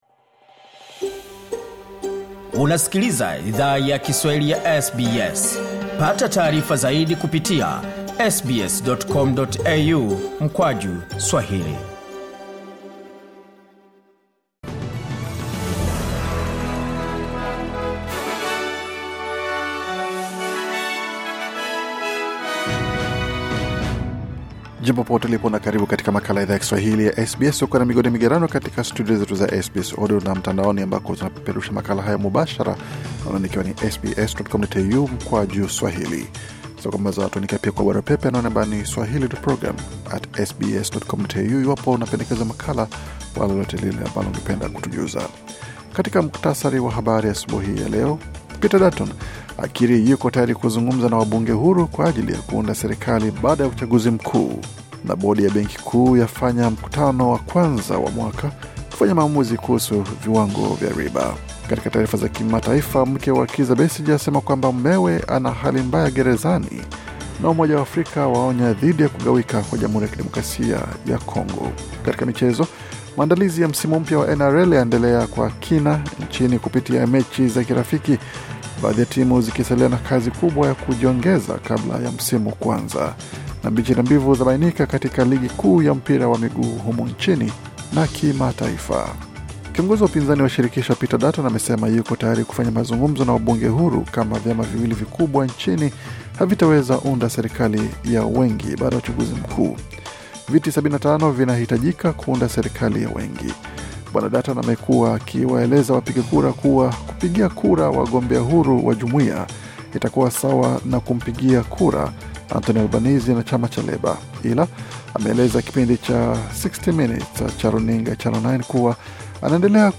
Taarifa ya Habari 17 Februari 2025